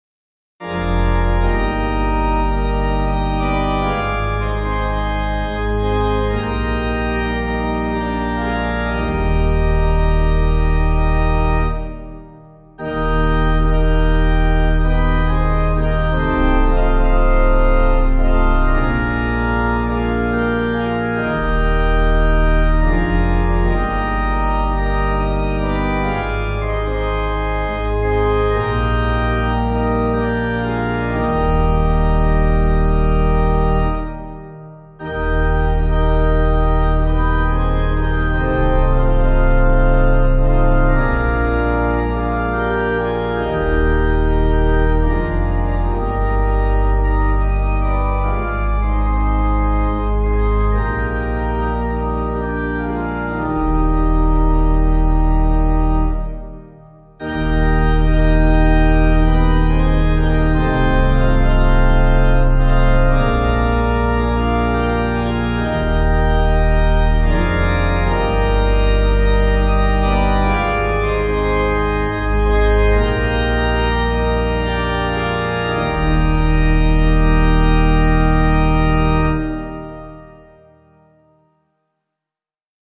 The melody changed meter from 9/8 to 3/4 and the rhythm was somewhat altered, but the lyrical support for the 8-6-8-6 iambic form (“ta-DA ta-DA ta-DA,” etc.) was cemented.